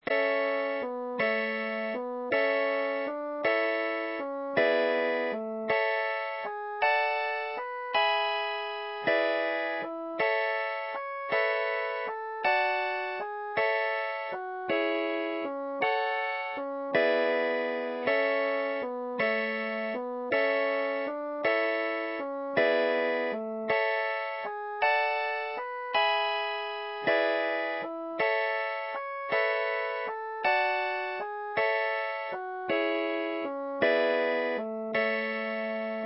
Inst=Instrumental (based on a MIDI file)
No Irish Need Apply Ballad HTML Page